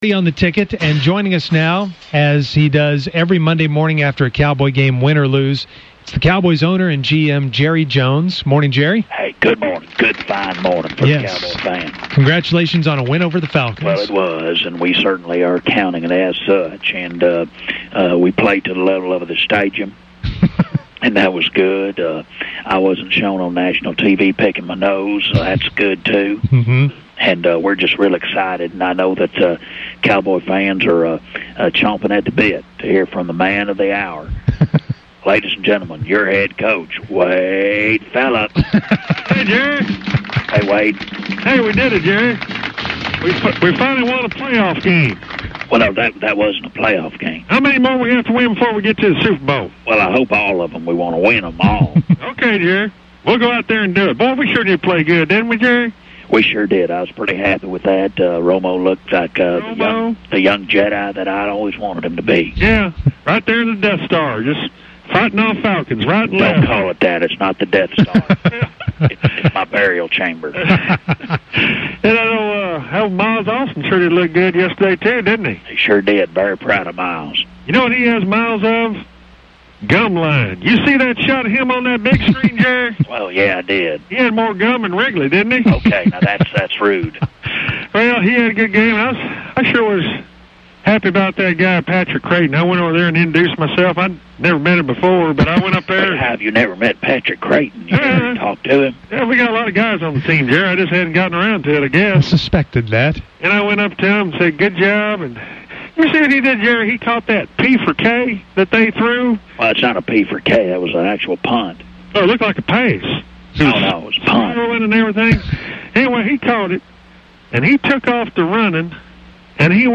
Musers have their normal Monday morning conversation with fake Jerry & fake Wade.